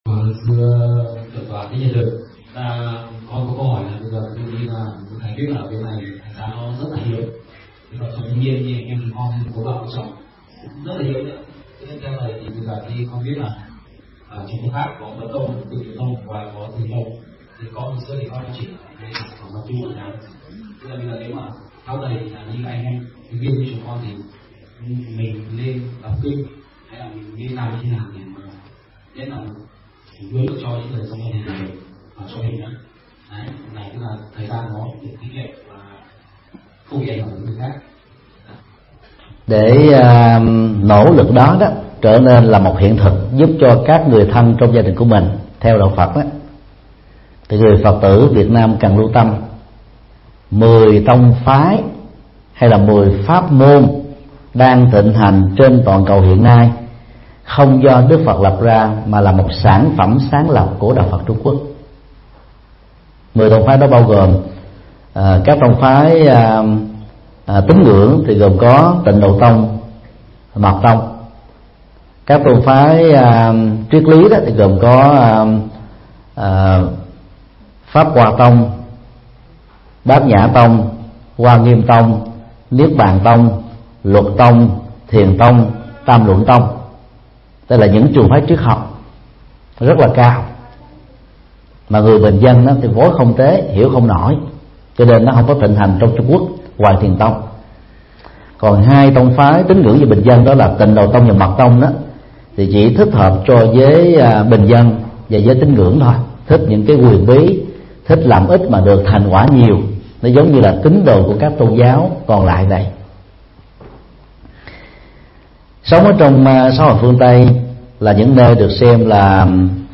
Vấn đáp: Hướng dẫn tụng kinh hằng ngày